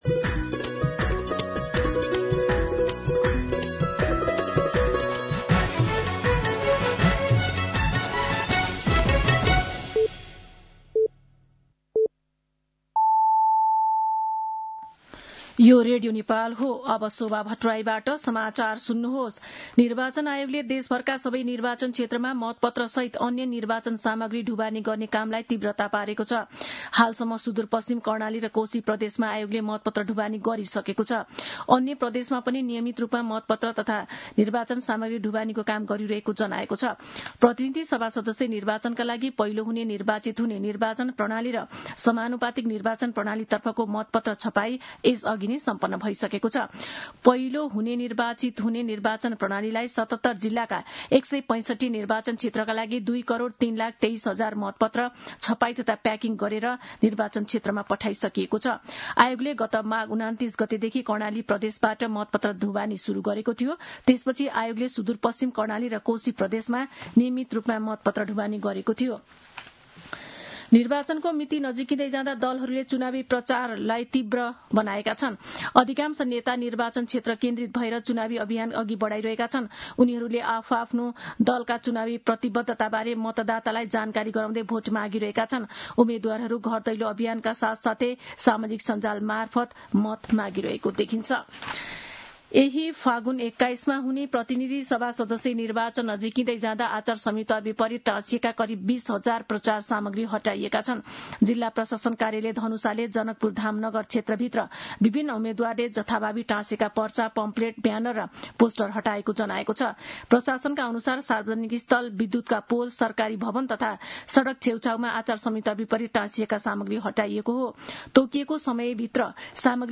मध्यान्ह १२ बजेको नेपाली समाचार : ९ फागुन , २०८२
12-pm-Nepali-News-4.mp3